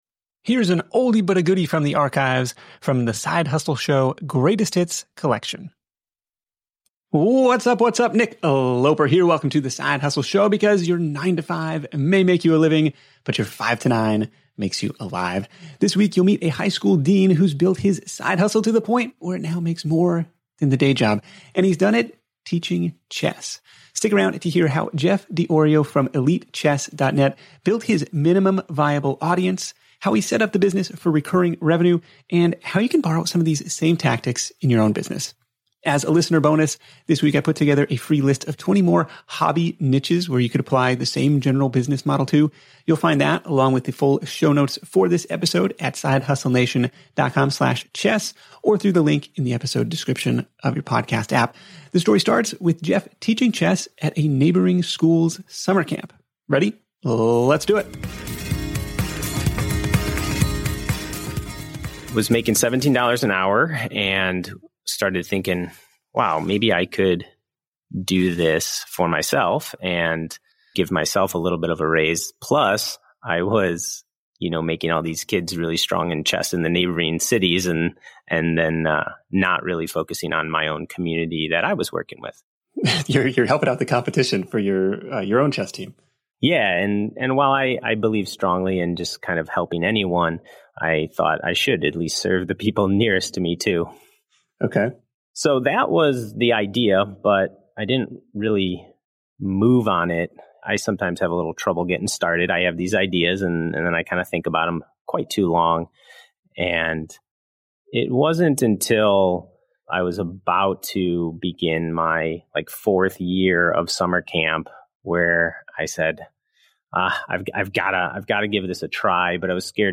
Tune in to The Side Hustle Show interview to hear: